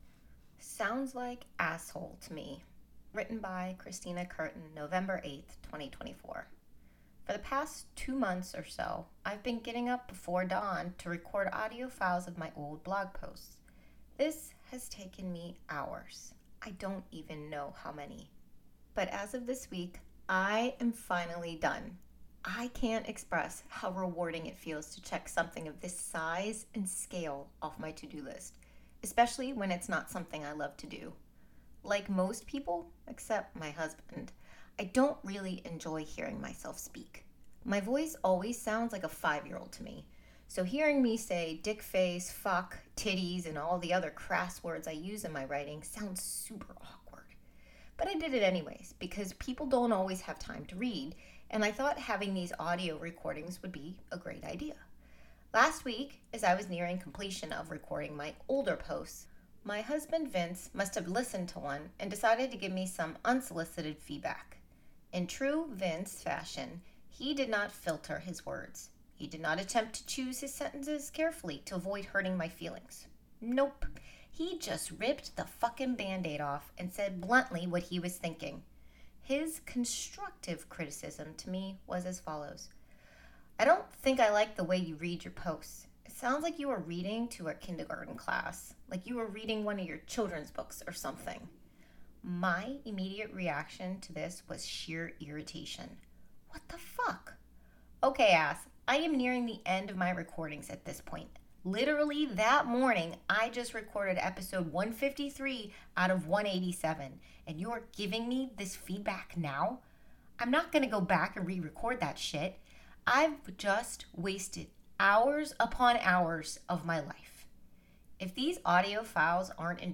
Audio could use some work, it needs more emotion and enthusiasm.